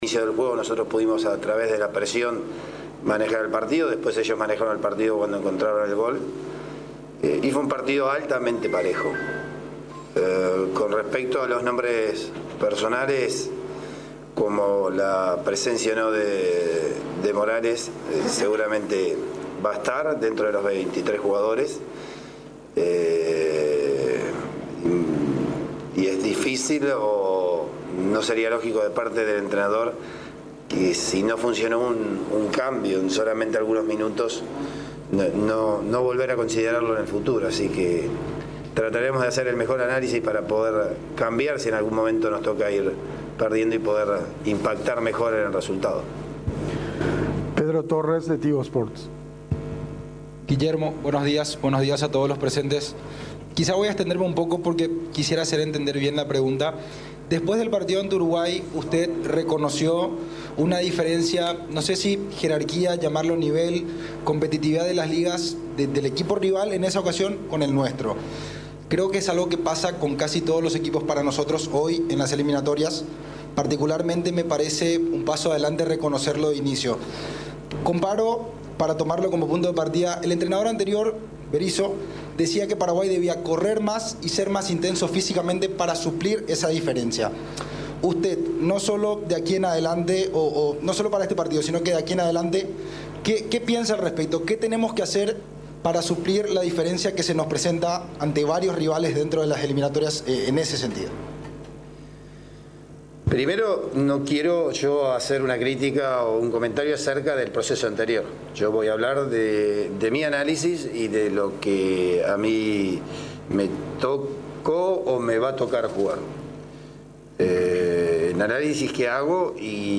El Director Técnico de la Selección Paraguaya de Fútbol, Guillermo Barros Schelotto, habló este lunes en conferencia de prensa, en la previa del duelo de mañana ante Brasil, por las Eliminatorias Sudamericanas rumbo al Mundial de Catar 2022.